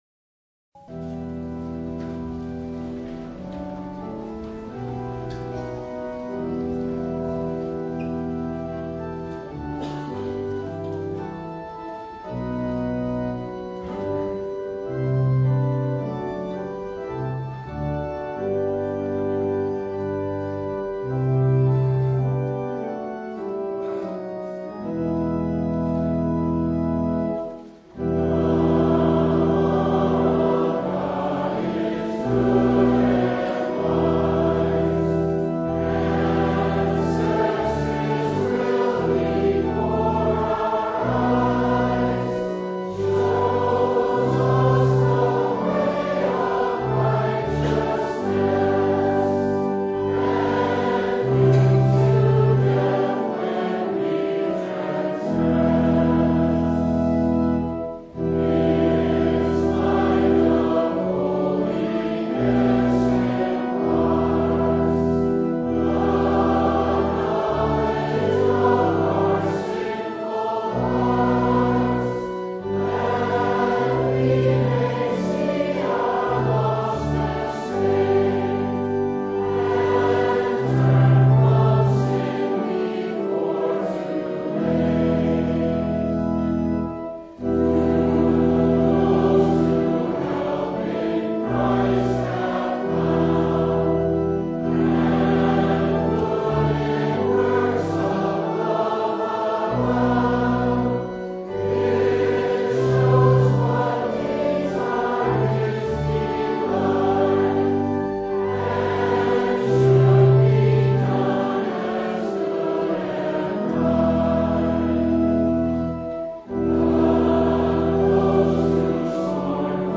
Service Type: Sunday
Download Files Notes Bulletin Topics: Full Service « The Cost of Discipleship Lost, Found, Rejoice!